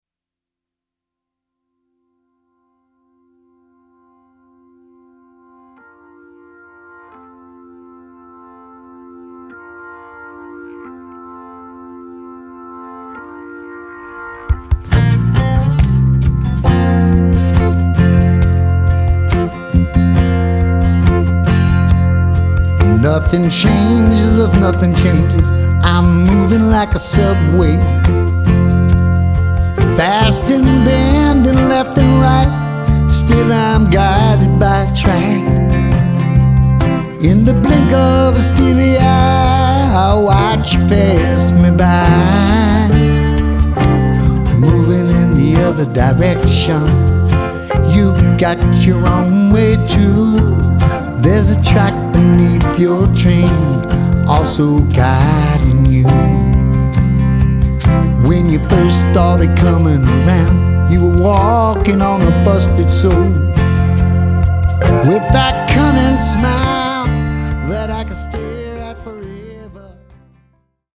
organ, electric piano